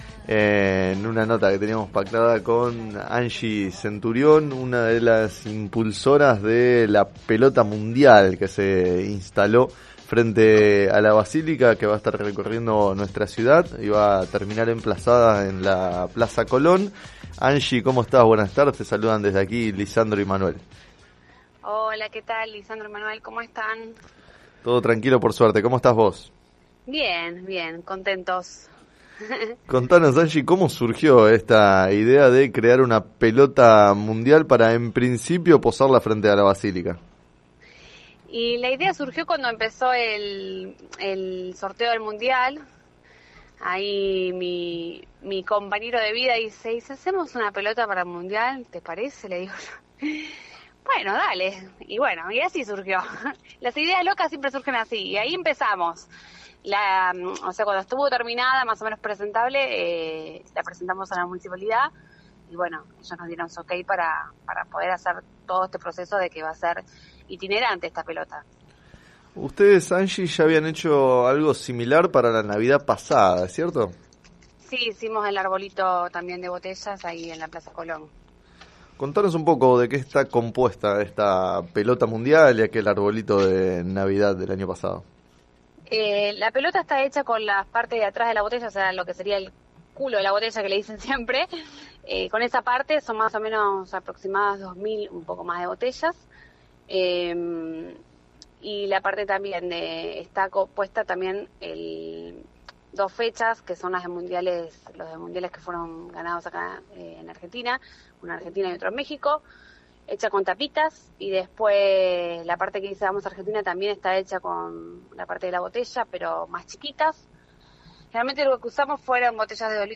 En declaraciones al programa “Sobre las cartas la mesa” de FM Líder 97.7